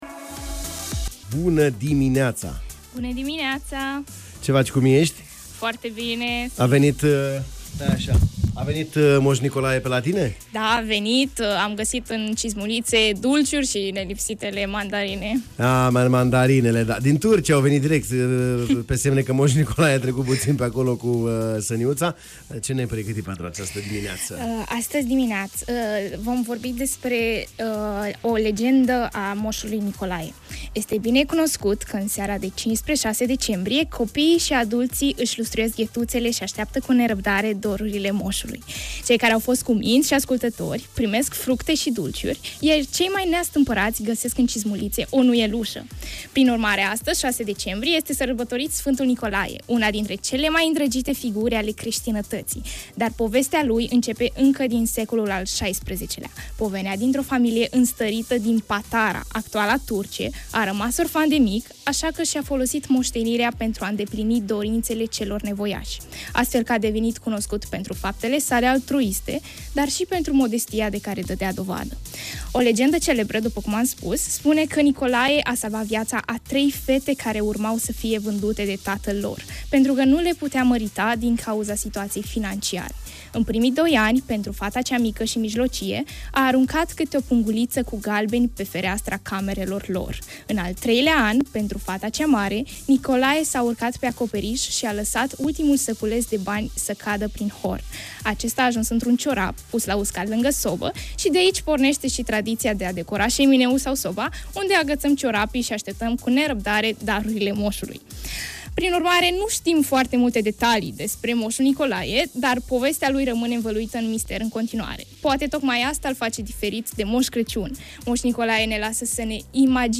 reporter ”Incubator” în direct la Bună Dimineața